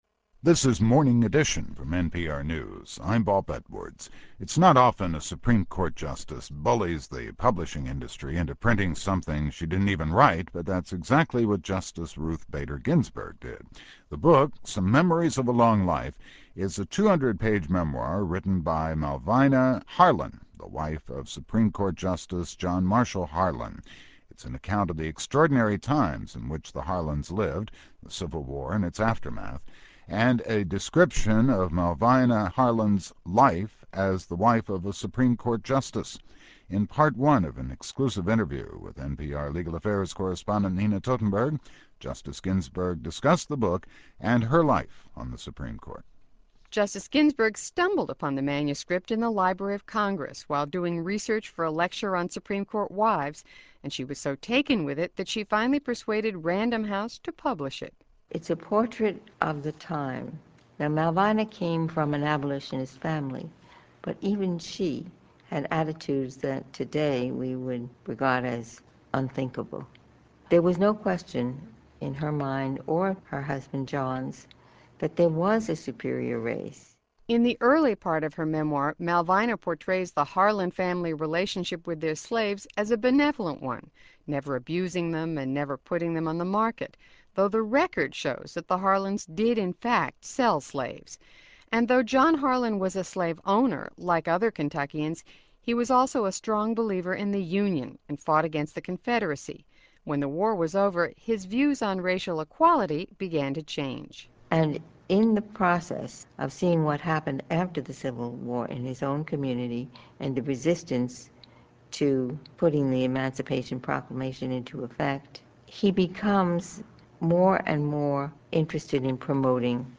Listen to Ruth Bader Ginsburg talk about Malvina Harlan on NPR: